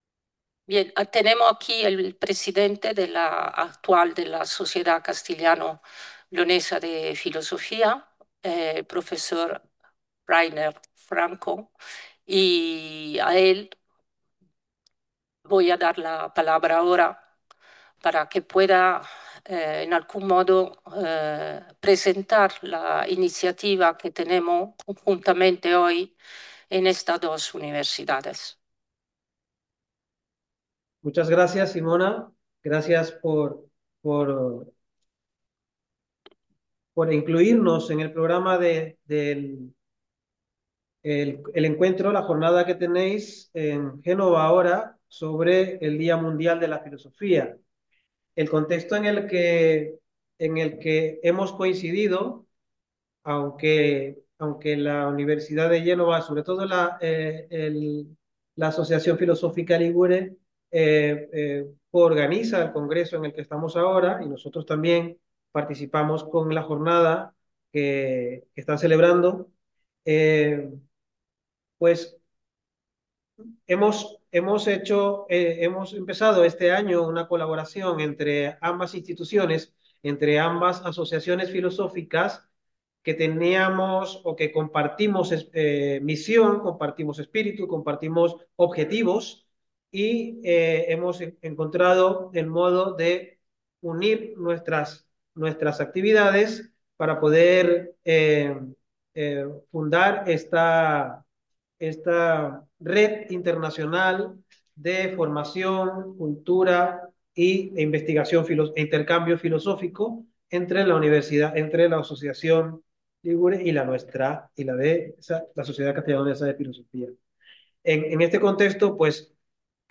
Registrazione integrale della conferenza “La Filosofia di fronte alle opportunità del futuro” per la Giornata Mondiale della Filosofia 2025, organizzata dall’Università di Genova e dall’Associazione Filosofica Ligure.